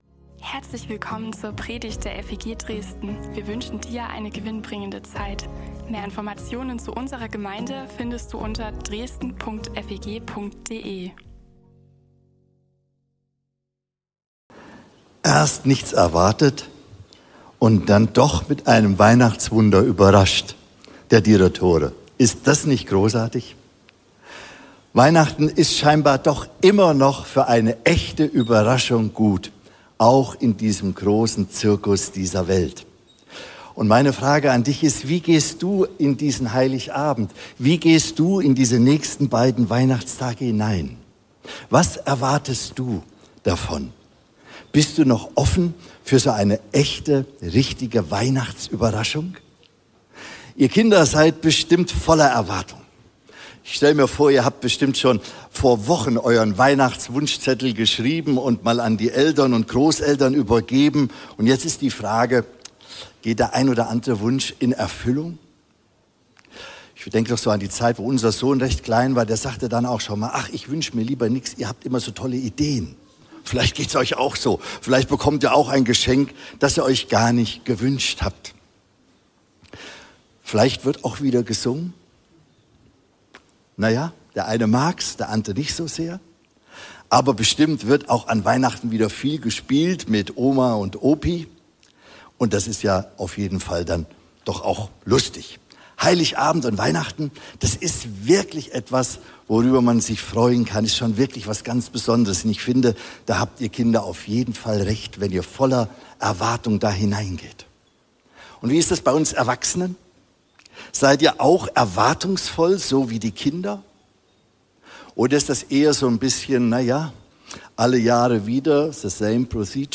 Christvesper